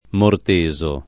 vai all'elenco alfabetico delle voci ingrandisci il carattere 100% rimpicciolisci il carattere stampa invia tramite posta elettronica codividi su Facebook mortasare v. (tecn.); mortaso [ mort #@ o ] — anche mortesare : morteso [ mort %@ o ]